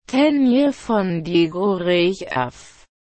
” ——谭雅·冯·提古雷查夫，于TV第12话 谭雅·提古雷查夫 （Tanya von Degurechaff [ˈ taː nja fɔn dɛɡuʁɛ'çaː f] 发音 ） [ 2 ] 是Carlo Zen所创作的小说 《 幼女战记 》 及其衍生漫画、动画的登场角色。